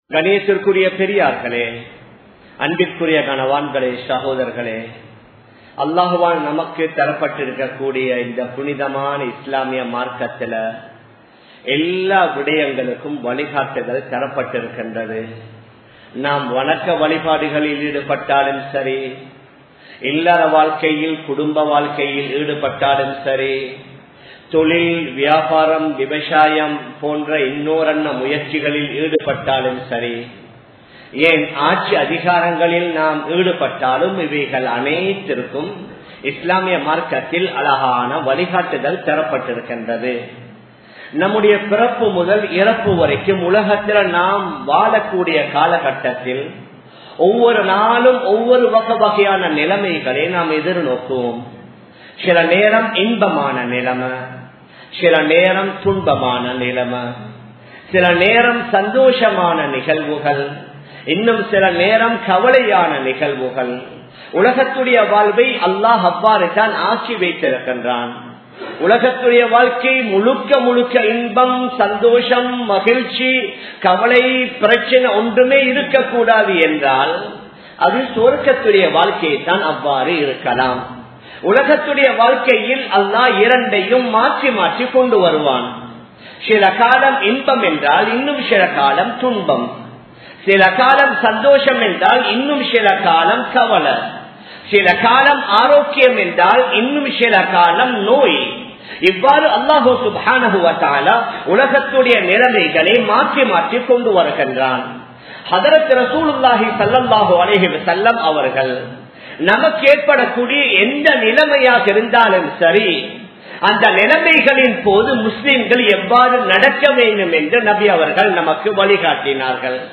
Dua vin Sakthi (துஆவின் சக்தி) | Audio Bayans | All Ceylon Muslim Youth Community | Addalaichenai
Colombo 11, Samman Kottu Jumua Masjith (Red Masjith)